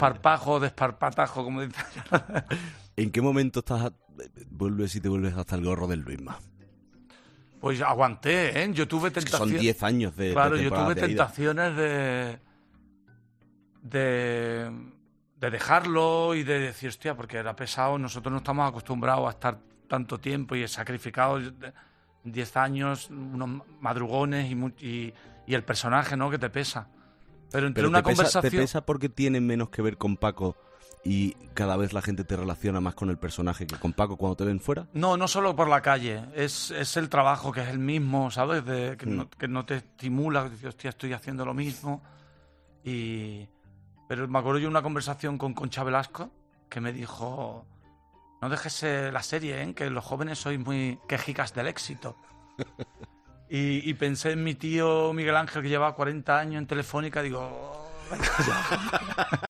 El actor y director ha pasado por los micrófonos de 'Herrera en COPE', en una semana marcada el estreno del nuevo podcast original de COPE